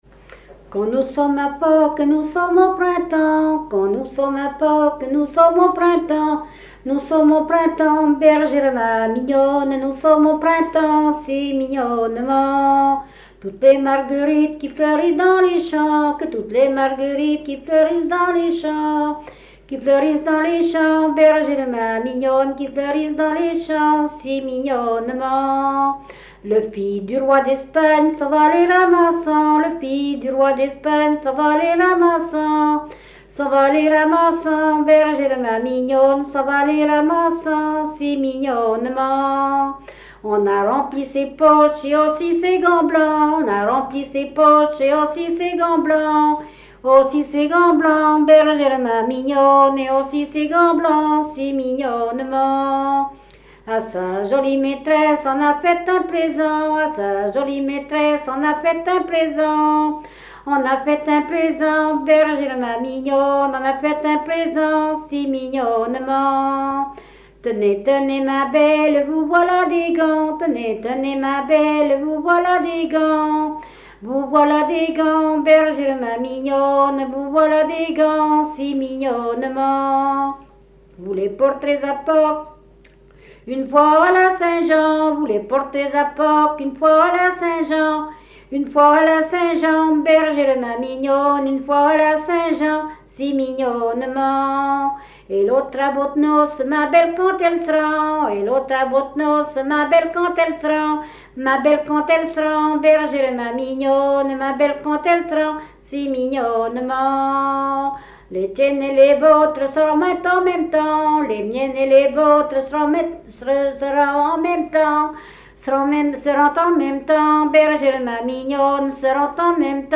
Fonction d'après l'analyste danse : ronde ;
Genre laisse
Catégorie Pièce musicale inédite